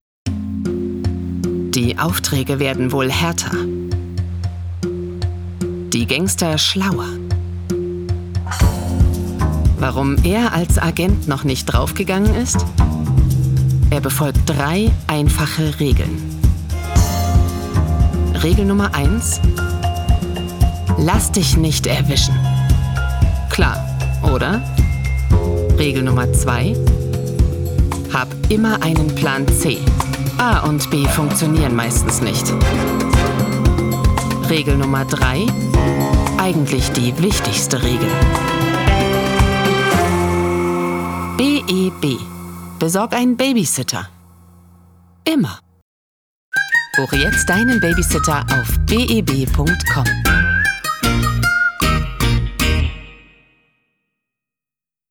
Sprechprobe: Werbung (Muttersprache):
I have a warm, medium-pitched timbre. My way of speaking is based on a certain care.